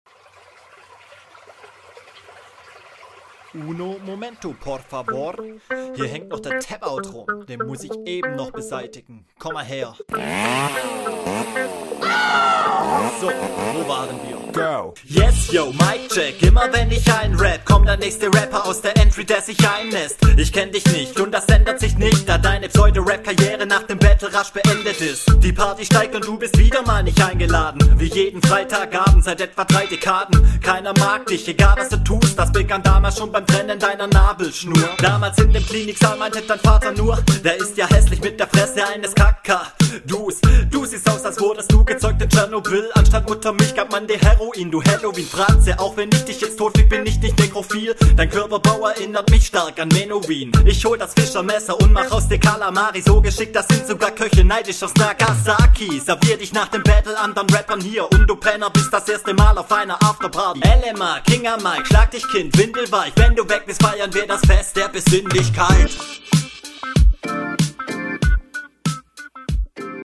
Ich finde den Beat echt nice und du Flowst darauf echt gut.
Stimmeinsatz passt zum Beat.
hahaha, super chillig. keine guten Angriffe, aber das, was du da gemacht hast ist toll.